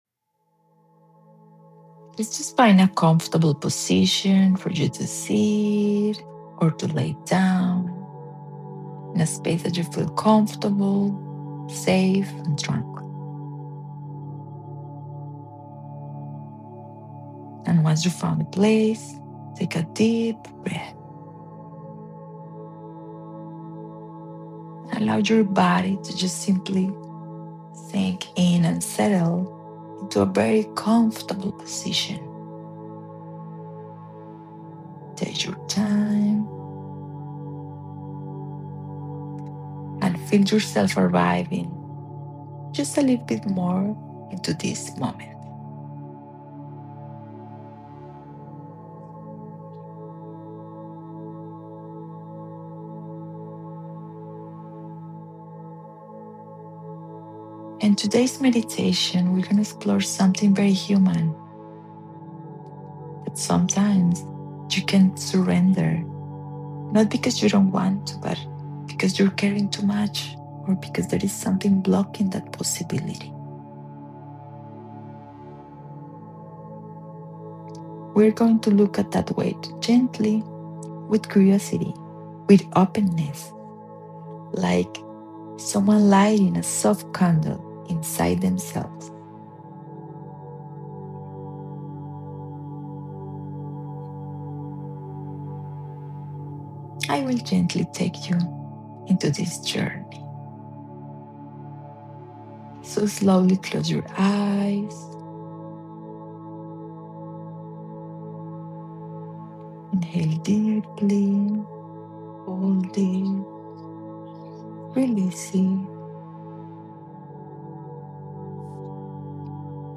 A guided meditation to release emotional tension, let go of old mental patterns, and shift your perspective through a therapeutic lens. Find calm, clarity, and a deeper sense of inner balance.